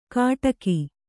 ♪ kāṭaki